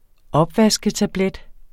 Udtale [ ˈʌbvasgə- ]